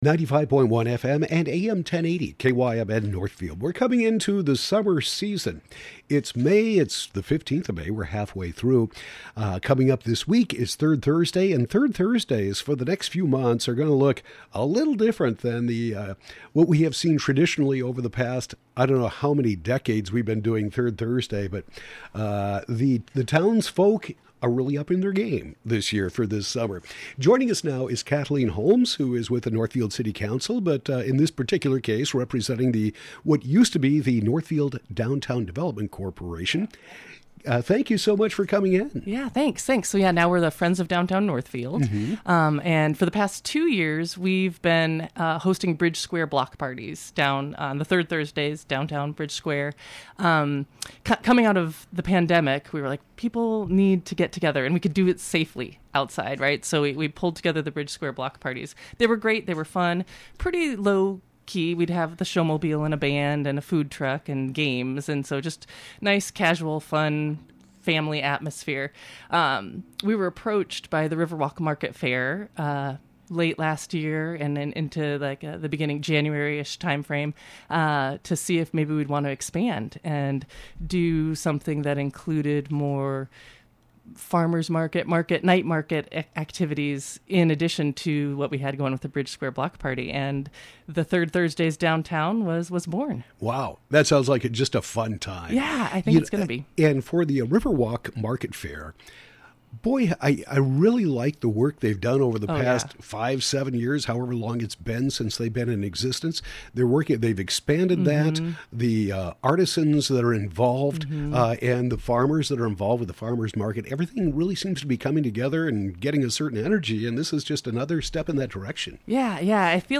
City Councilor Kathleen Holmes, who also sits on the Friends of Downtown Northfield board, discusses the Third Thursday Downtown events which will include vendors from the Riverwalk Market Fair.